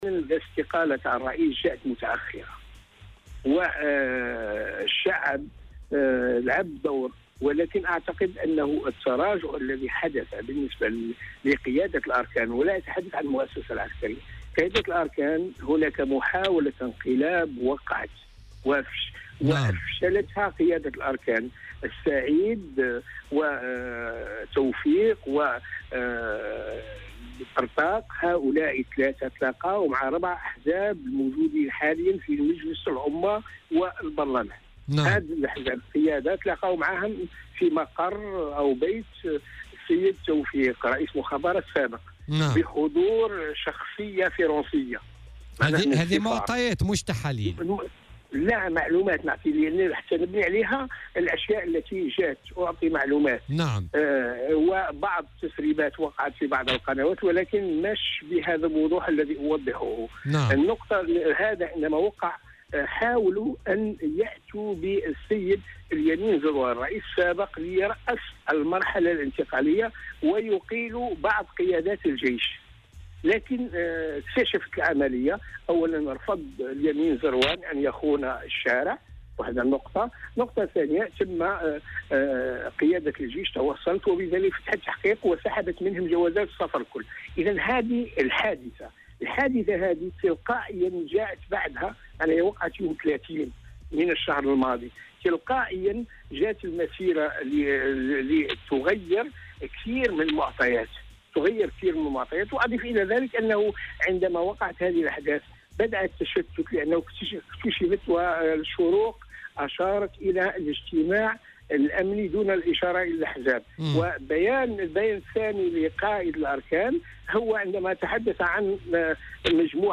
وتحدث في مداخلة له اليوم في برنامج "بوليتيكا" على "الجوهرة أف أم" عن وجود ما أسماه "محاولة انقلاب" أفشلتها قيادة الأركان، وفق تعبيره.